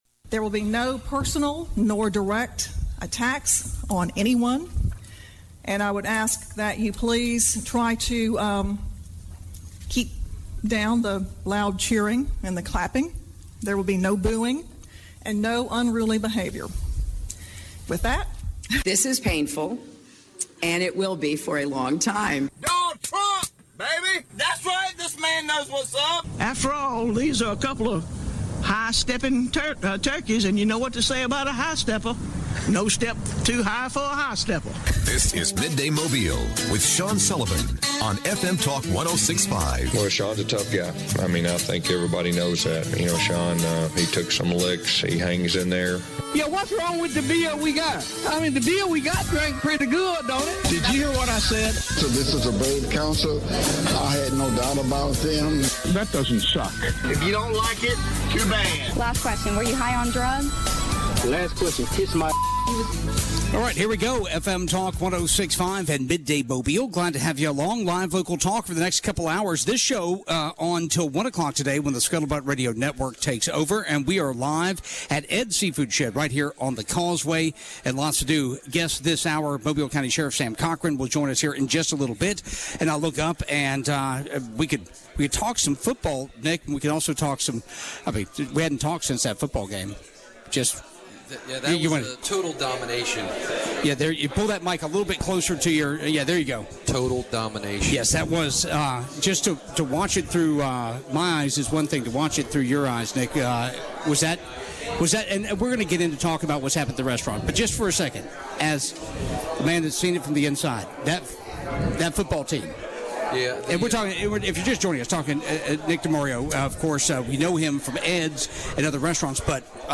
Ask the Sheriff from Ed's Seafood Shed